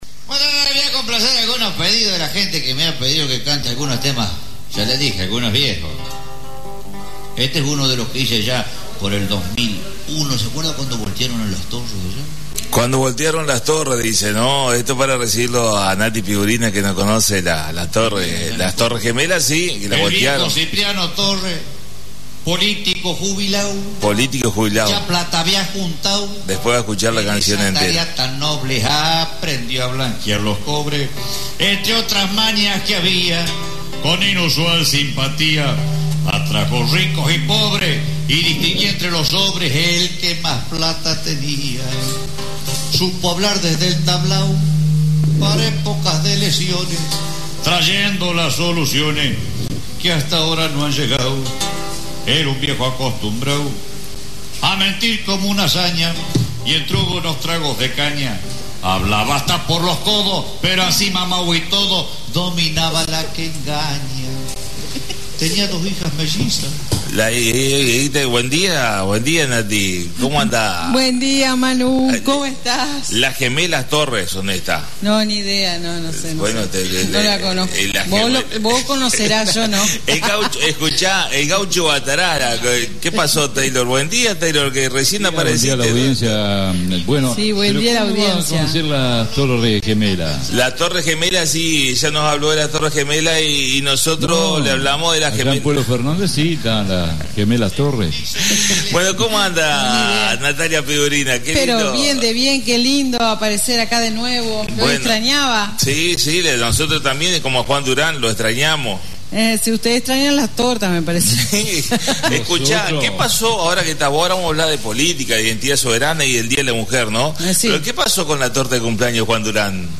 La visita a la Radio de Natalia Pigurina Diputada (s.) de Identidad Soberna previo al 8M